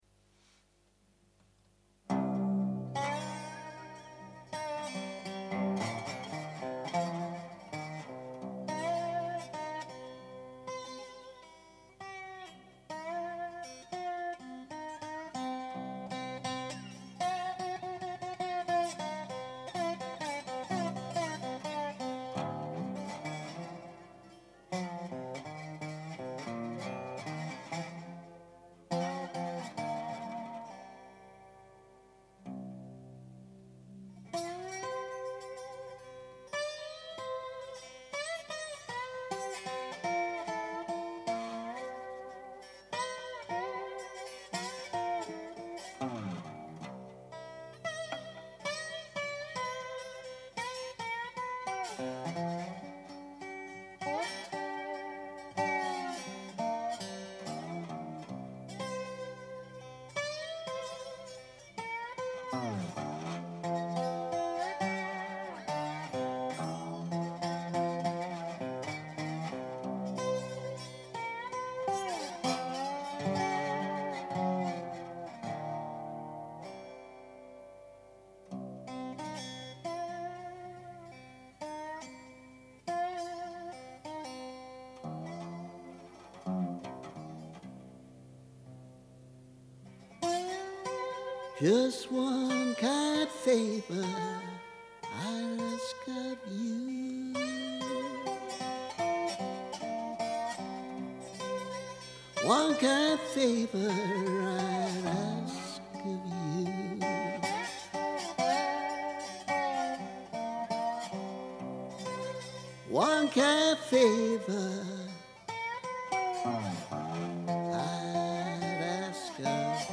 One kind favour [demo-ish]